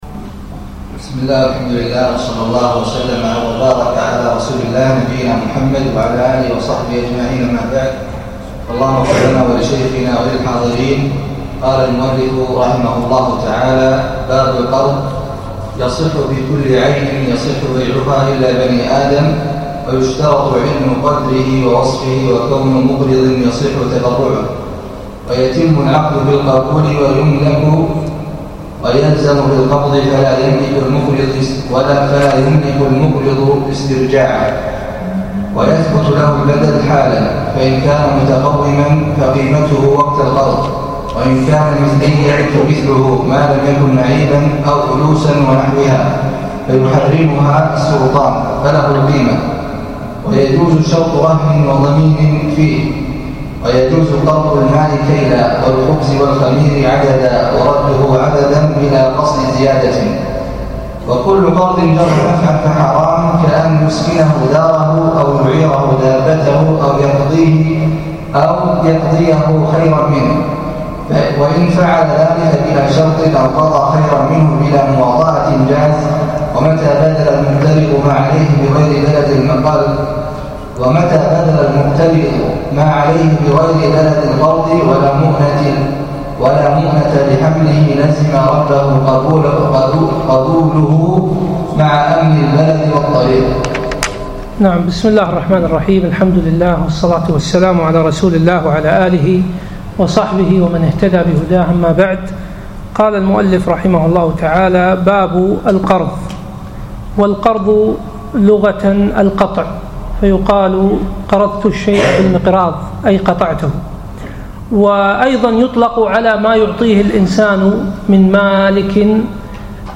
السبت 27 ربيع الثاني 1437 الموافق 6 2 2016 مسجد سالم العلي الفحيحيل
الدرس الخامس